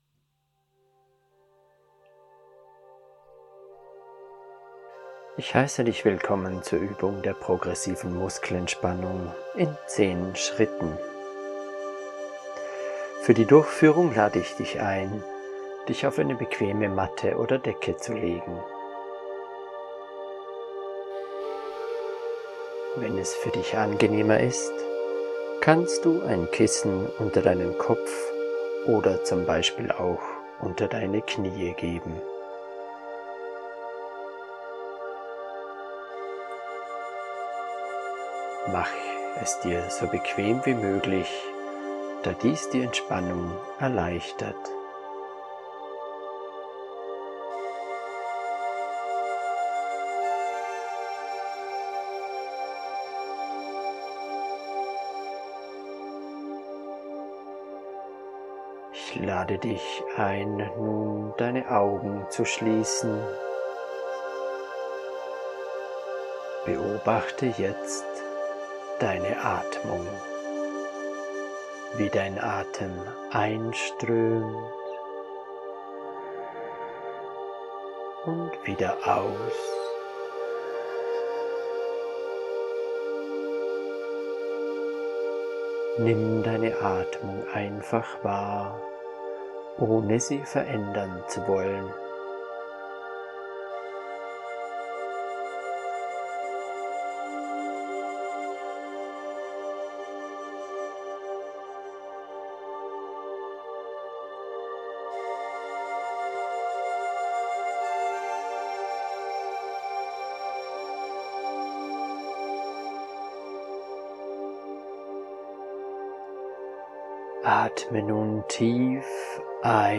progressive-ME-mit-musik.mp3